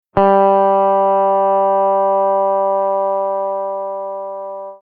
Simply click the play button to get the sound of the note for each string (E, A, D, G, B and E).
G String
g-note.mp3